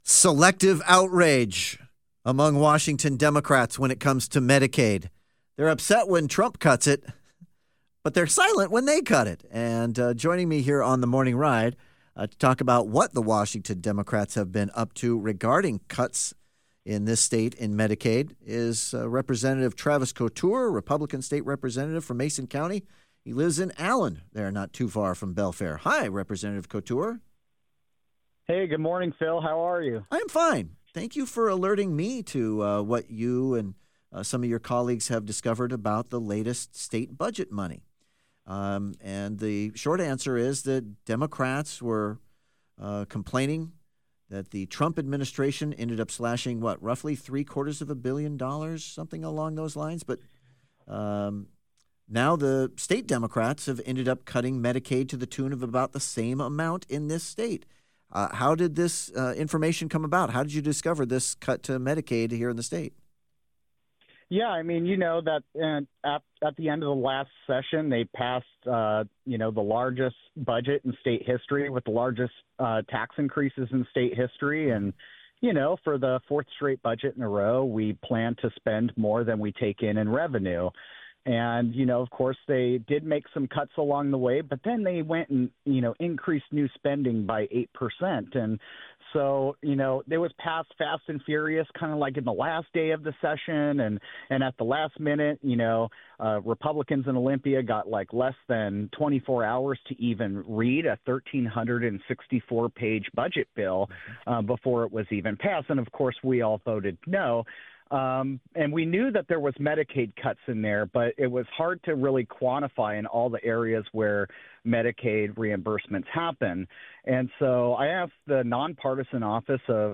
Rep. Travis Couture joined The Morning Ride to blast the Democratic budget approach, arguing that they have eliminated more than $750 million from Medicaid services while simultaneously shifting blame to Washington, D.C. Couture emphasized that this represents a troubling irony: vulnerable communities are bearing the brunt of reductions, at the same time the blame for systemic shortfalls is being deflected.